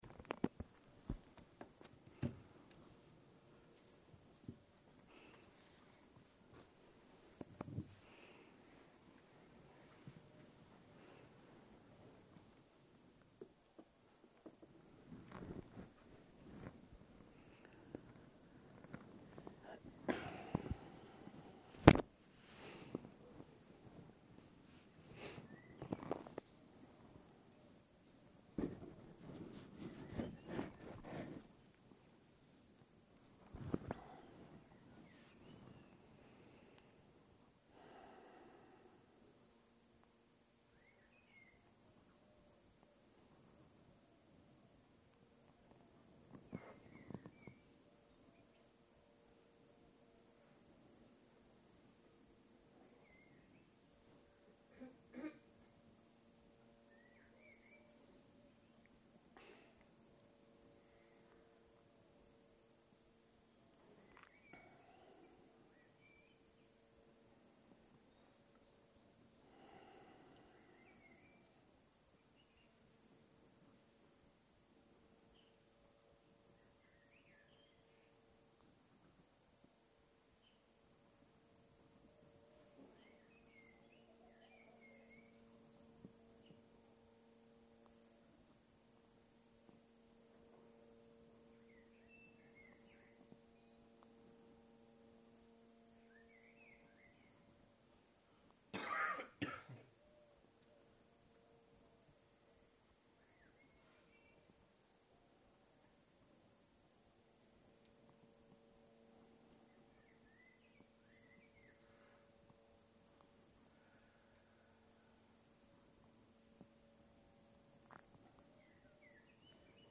07.03.2023 - יום 5 - צהרים - מדיטציה מונחית - תשומת לב פתוחה - הקלטה 12